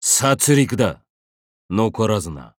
cu_chulainn_alter_voice_noble_phantasm_card.mp3